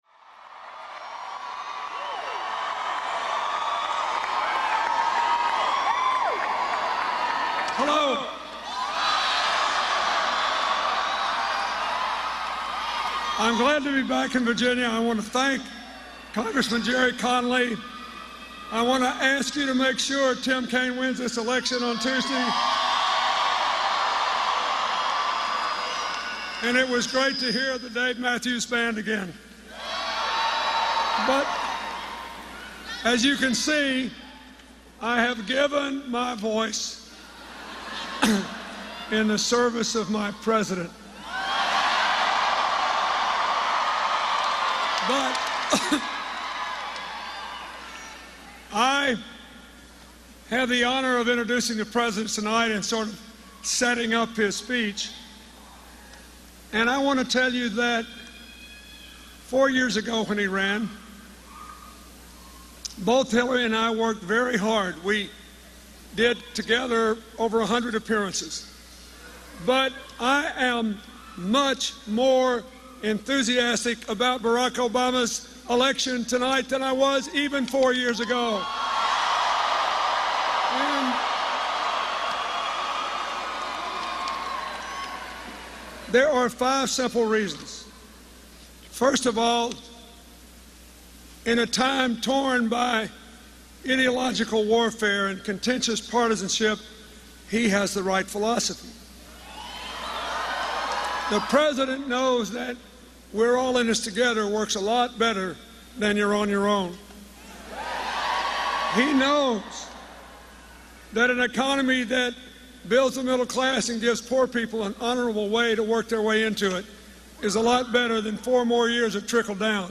Former U.S. President Bill Clinton joins President Barack Obama at a campaign event held in Bristow, Virginia